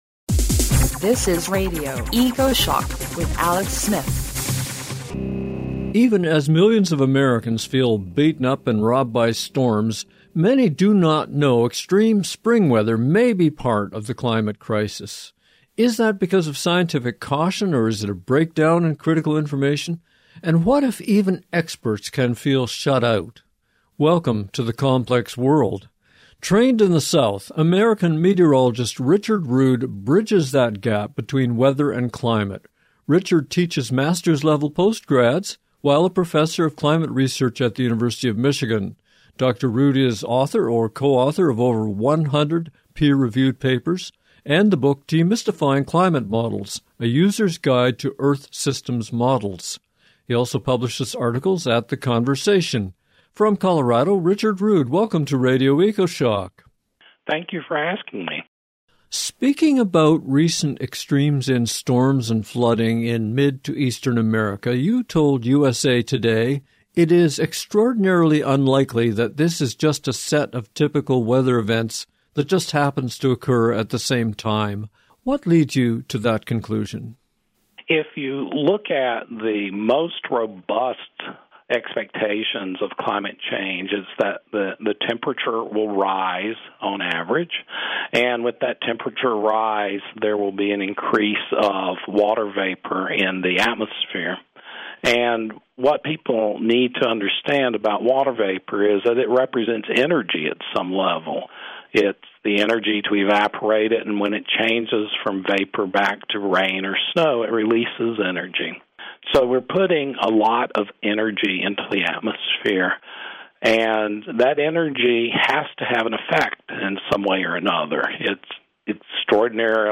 30 minute interview